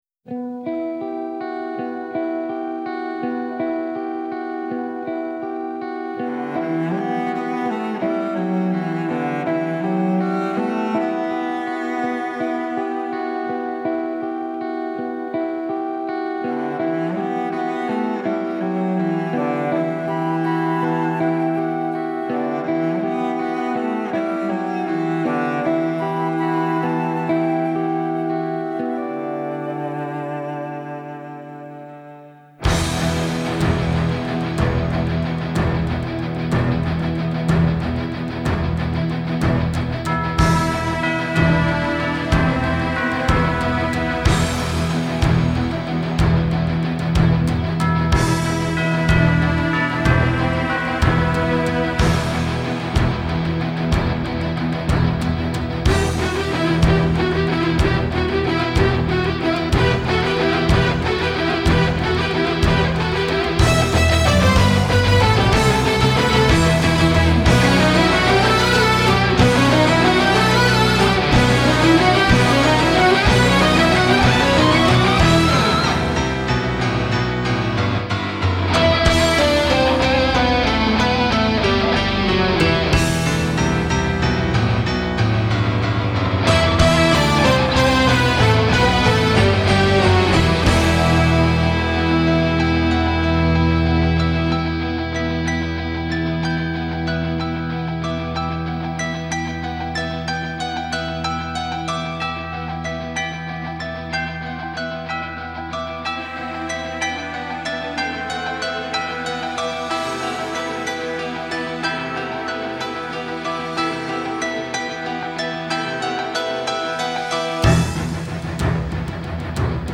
[Instrumental]